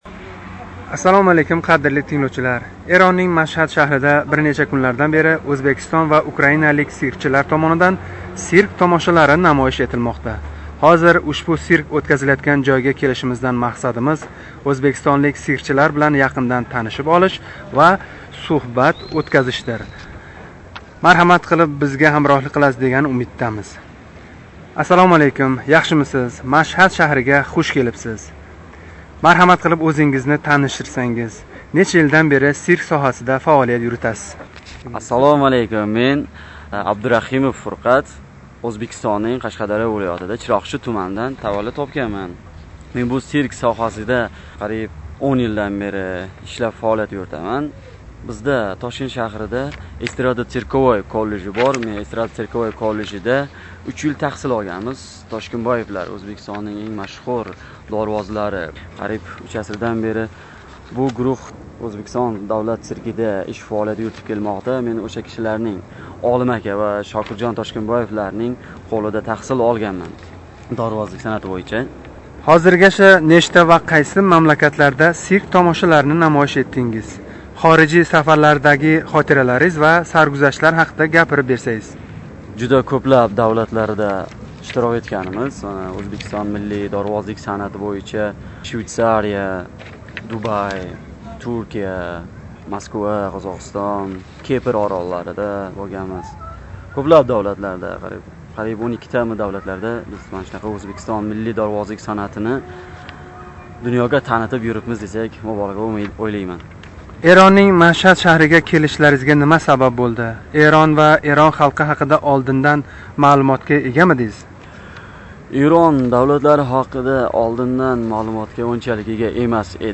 Ана шу суҳбатни қадрли муштарийларимиз эътиборига ҳавола этамиз.